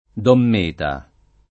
dqm m%ta] — pn. chiusa dell’-e- per regolare continuaz. del lat. meta con -e- lunga, nome generico di oggetti di forma conica o piramidale, conservato per tradiz. pop. (orale) nei sign. propri della vita dei campi, ricostruito invece per via lett. (scritta), e quindi con -e- aperta, nei sign. fig. («termine», ecc.) facenti capo a quello di «colonnetta» del circo